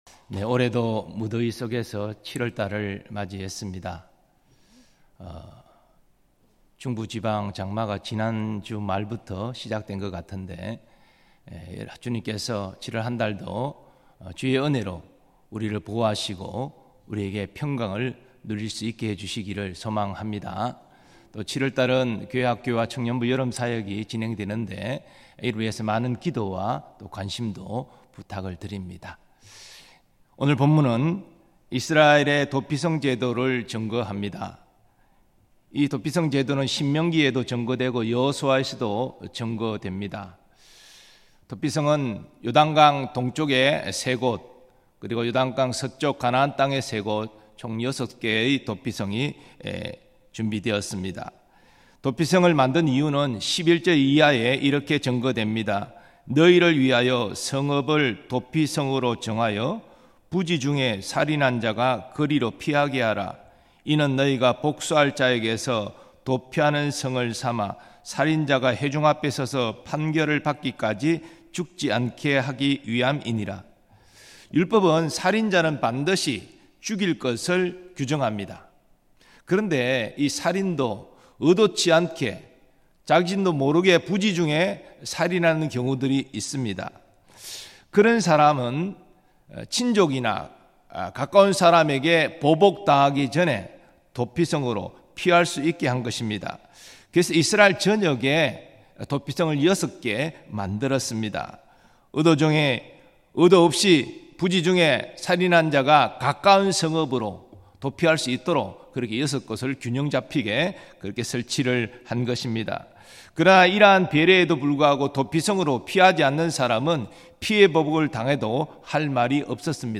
2024년 7월 1일 전교우 새벽기도회
예수님은 우리의 영원한 피난처입니다 음성설교 듣기 MP3 다운로드 목록 이전 다음